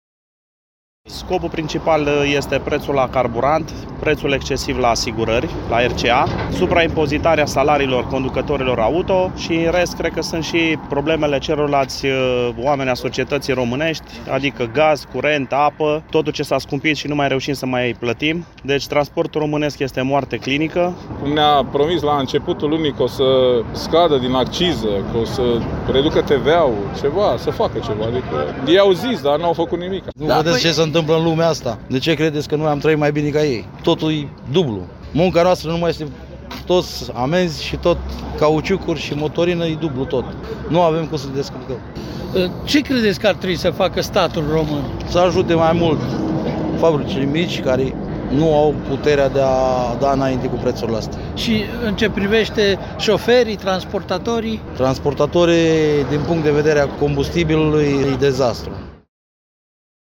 protest-transportatori.mp3